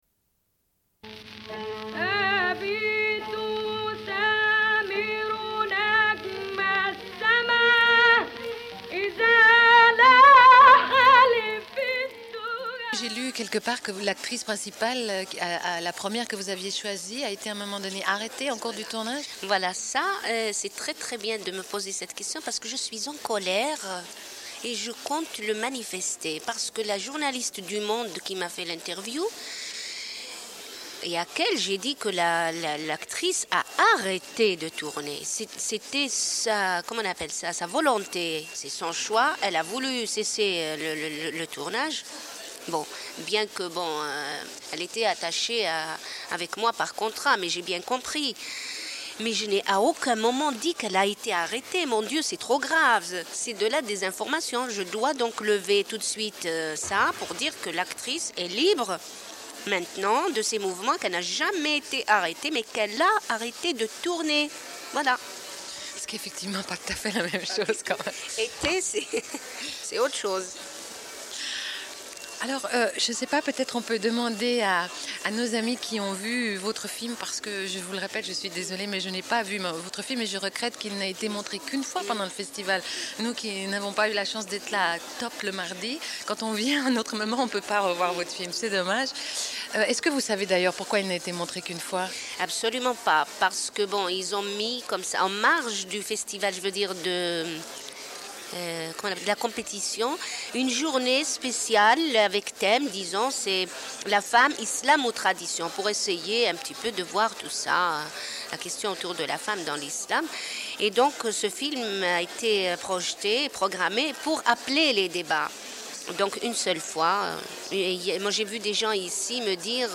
Une cassette audio, face B28:44